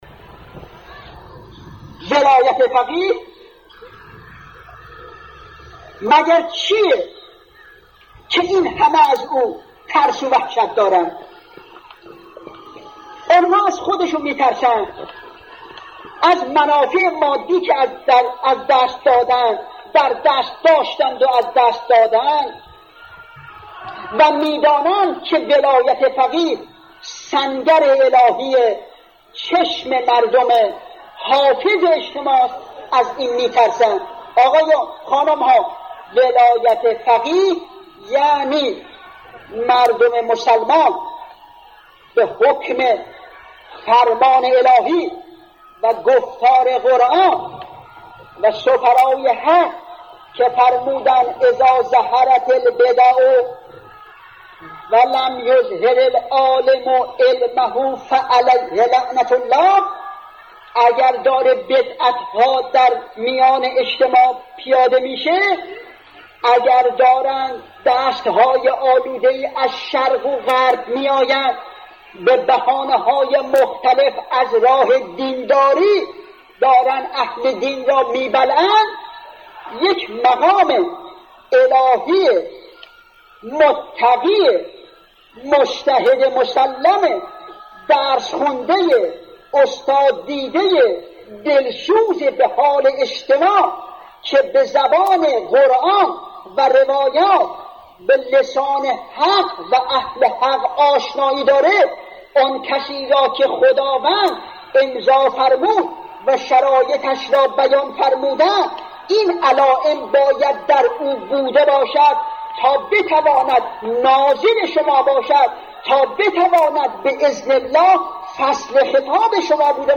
به گزارش خبرگزاری حوزه، مرحوم علامه حسن زاده آملی در یکی از سخنرانی های خود به موضوع «اهمیت و جایگاه ولایت فقیه» اشاره کرده‌اند که تقدیم شما فرهیختگان می شود.